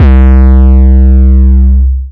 TM88 SpaceDist808.wav